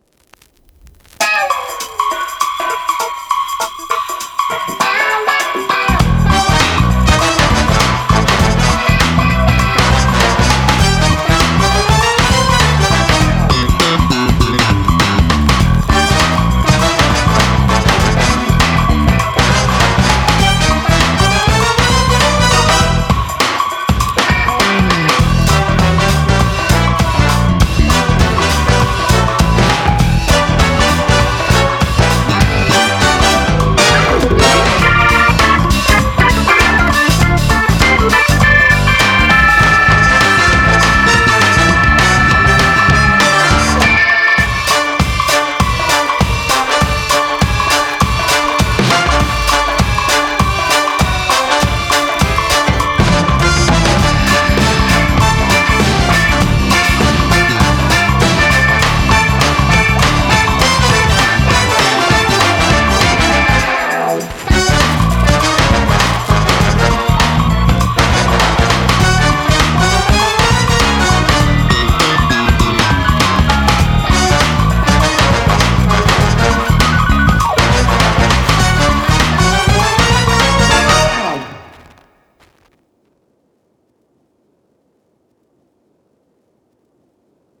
Jazz / Funk pieces
Brute-Force-Funk.wav